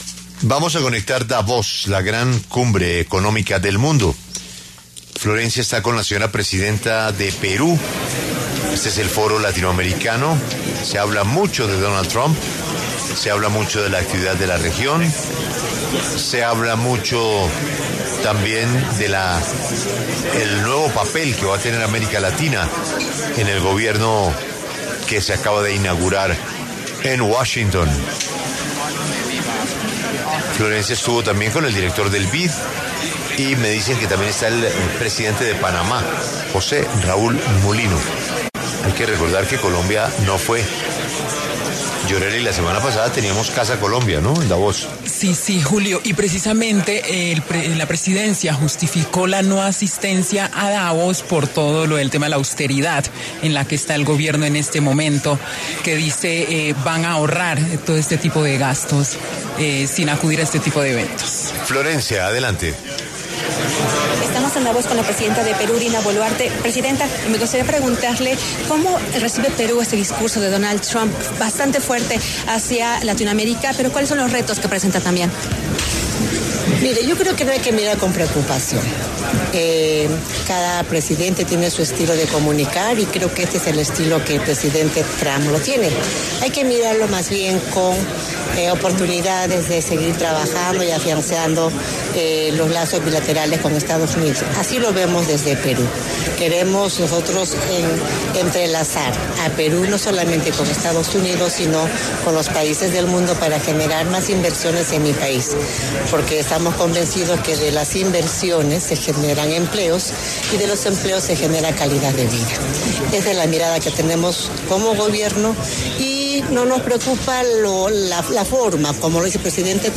La presidenta de Perú, Dina Boluarte, pasó por los micrófonos de La W en el marco del Foro Económico Mundial (Foro de Davos) donde se habla mucho del nuevo papel que va a tener Latinoamérica en el nuevo Gobierno de Estados Unidos.